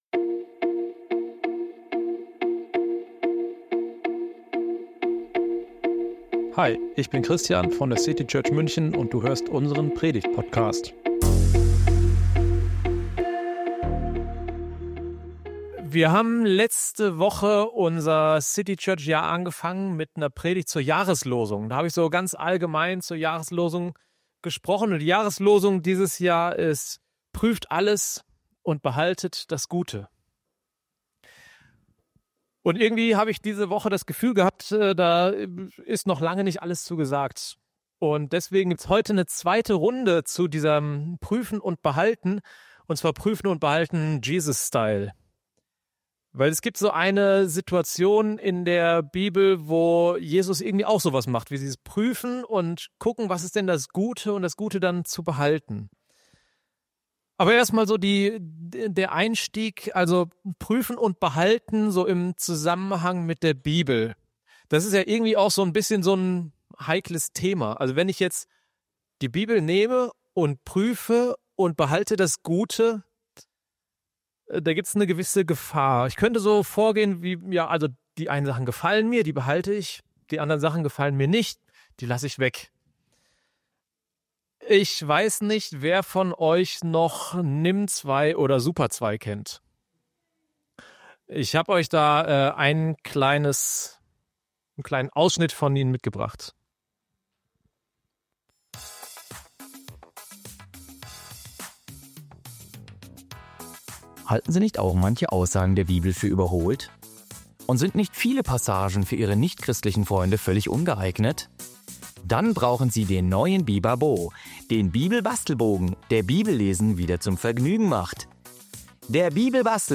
In diesem Gottesdienst schauen wir mal, wie Jesus das auch in seiner Bergpredigt gemacht hat und was wir davon lernen können.